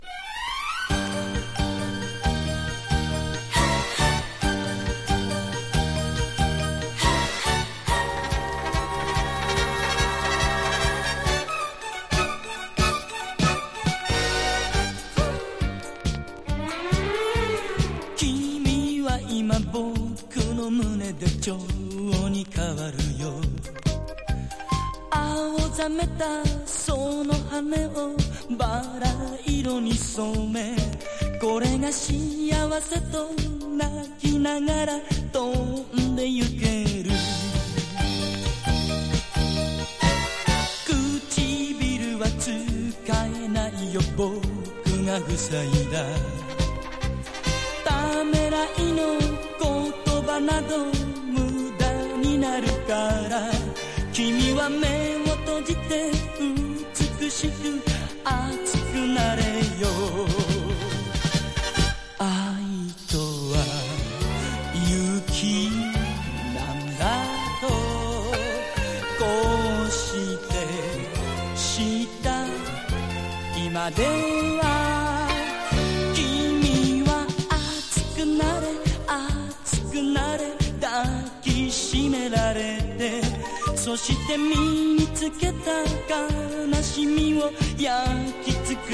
POP
ポピュラー# 70-80’S アイドル